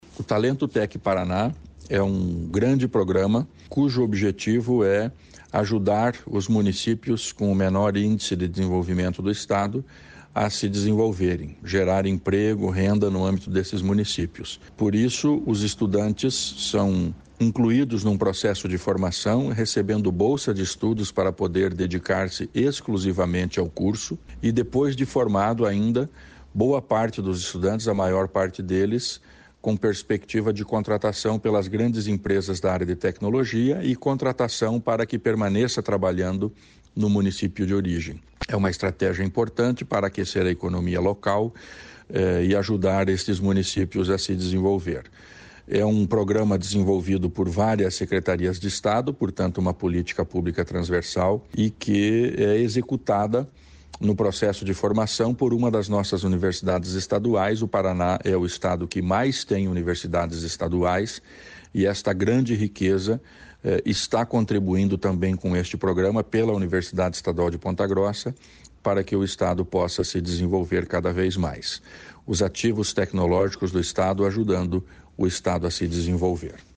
Sonora do secretário da Ciência, Tecnologia e Ensino Superior, Aldo Bona, sobre o Talento Tech-PR